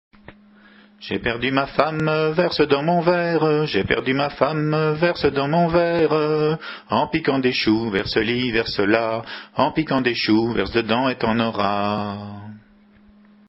Chants à répondre